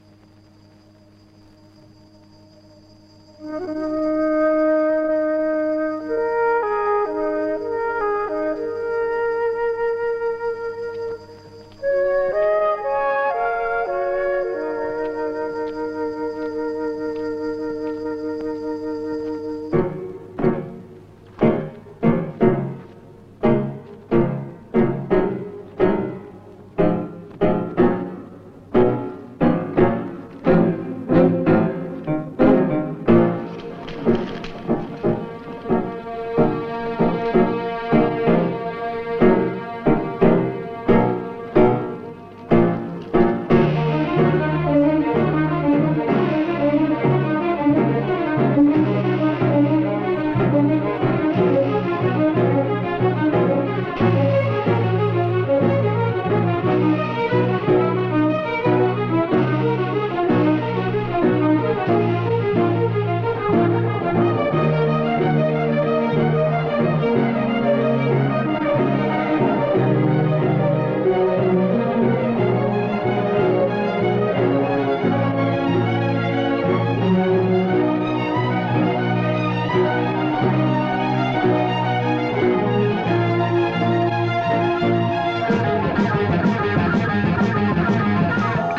il fallait reconnaître la bande originale
rock progressif